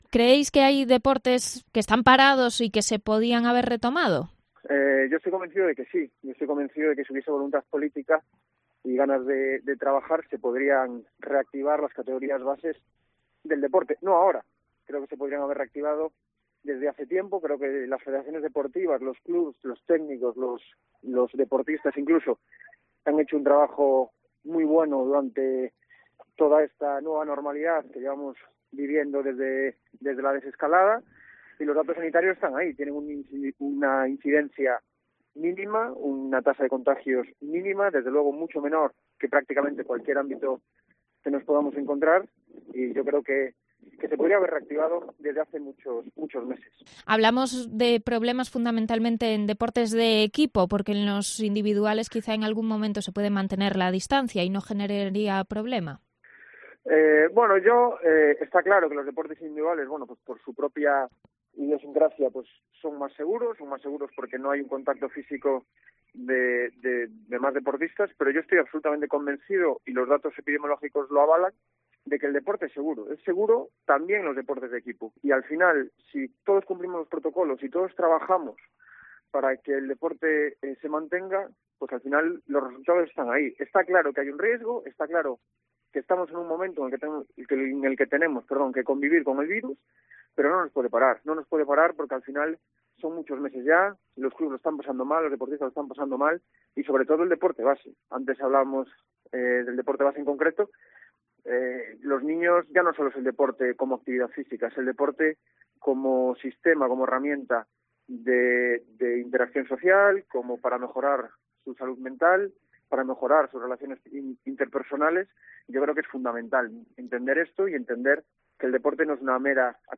El concejal popular Guille Juncal ha explicado en esta emisora las condiciones que se les exige a los distintos deportes para poder entrenar y competir y se ha quejado de que a determinadas categorías de base no se les permita usar las instalaciones municipales de Pontevedra, a diferencia de lo que ocurre en el resto de concellos.